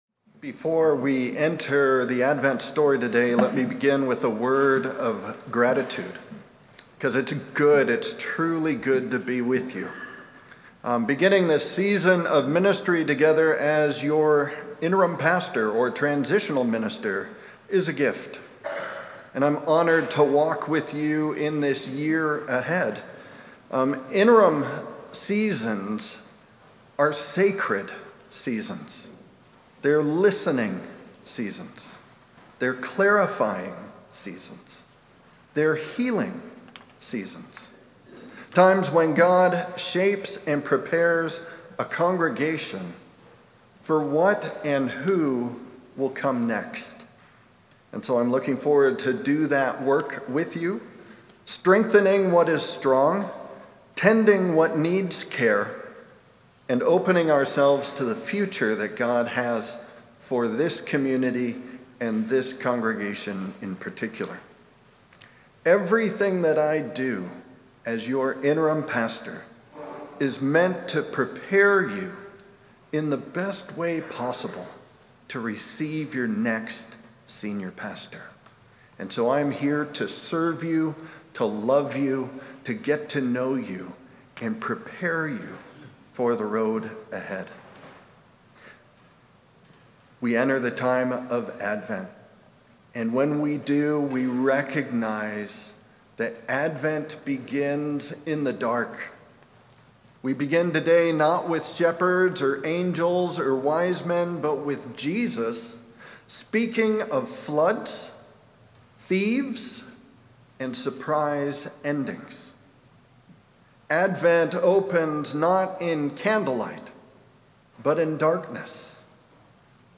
Audio Sermons details